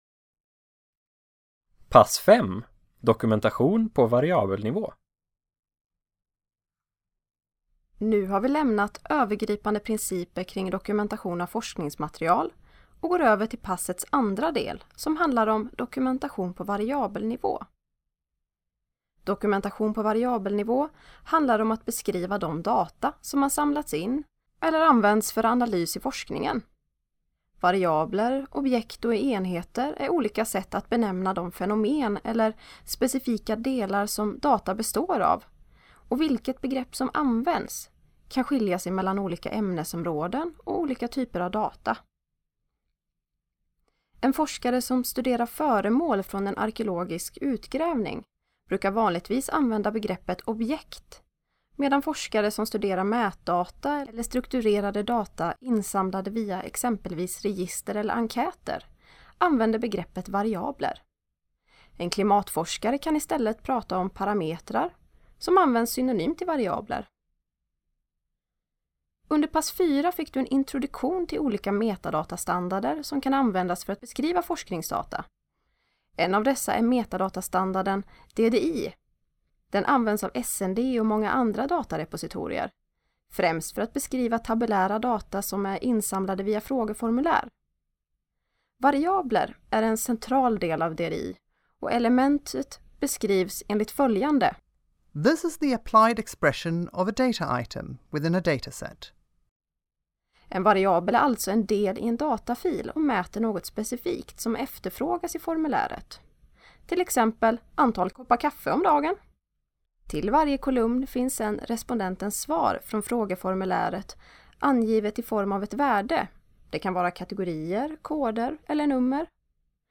Presentationens text som PDF-fil Presentationens bilder som PDF-fil Presentationen som ljudfil